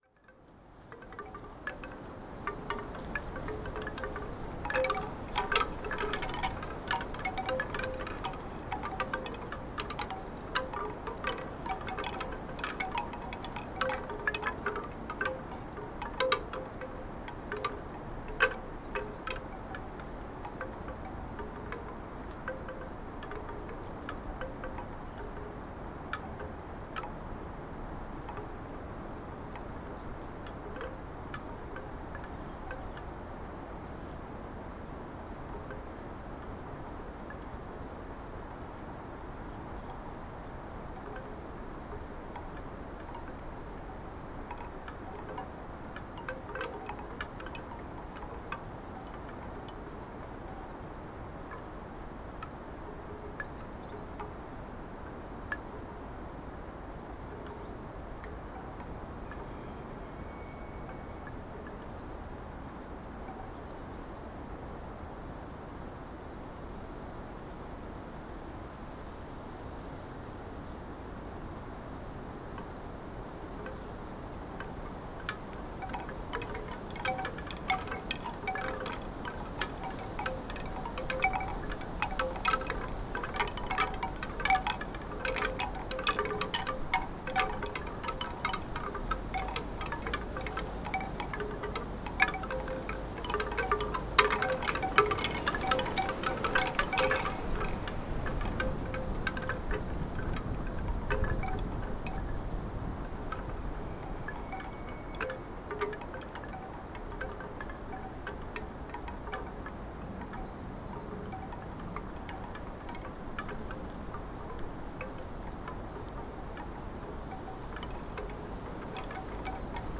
Bamboo wind chimes at Hunter's Tor, Teign Gorge Sound Effect — Free Download | Funny Sound Effects
Invigorating late winter breeze with the airy dry rattling and sonorous b